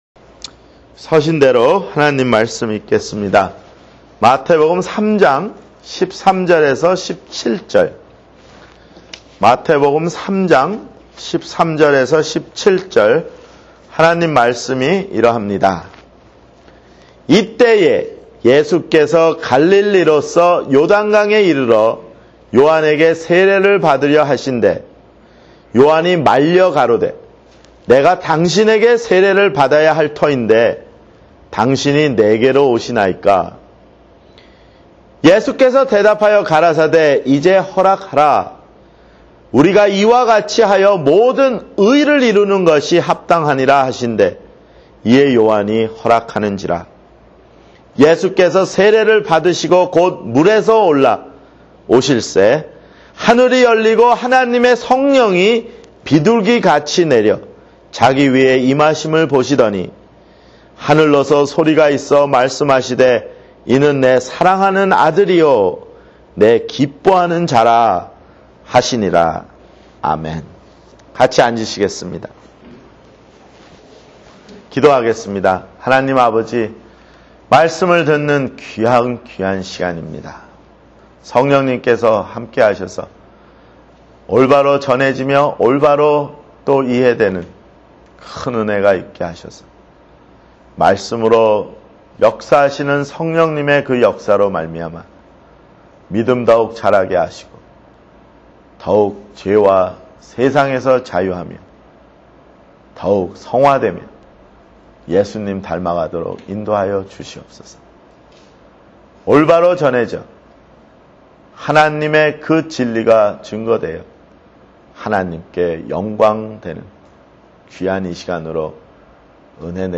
[주일설교] 마태복음 (8)